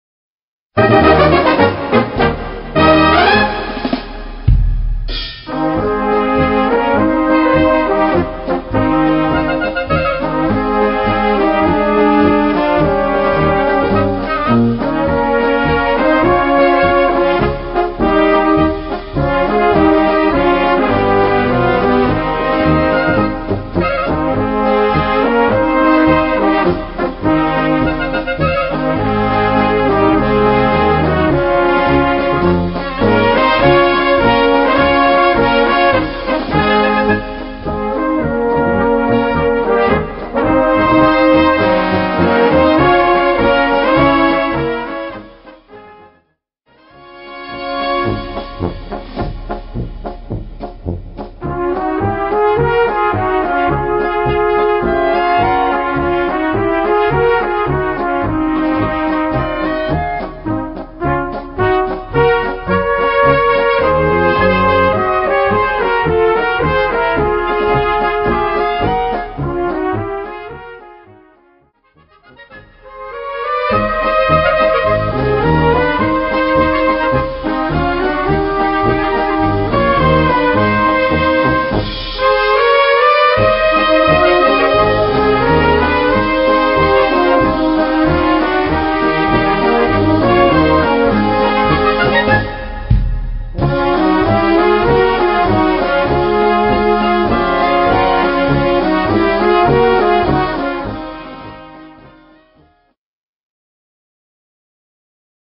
Gattung: Bravourpolka
Besetzung: Blasorchester